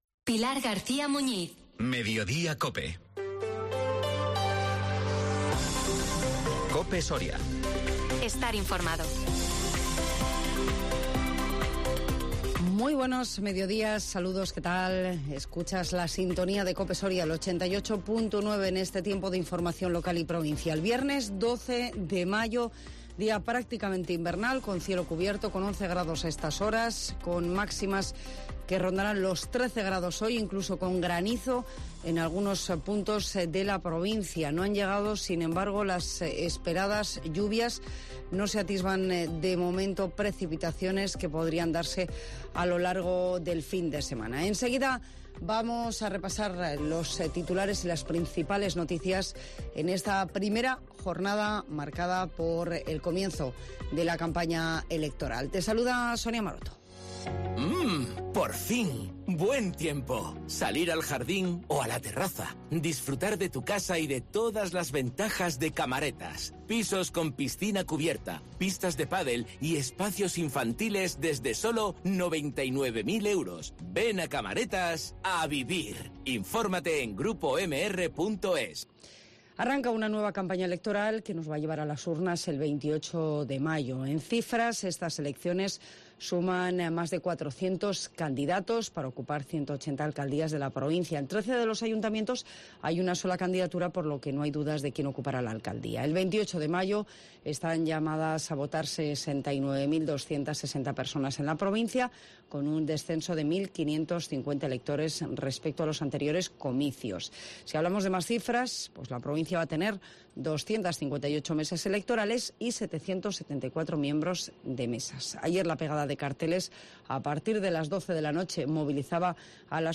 INFORMATIVO MEDIODÍA COPE SORIA 12 MAYO 2023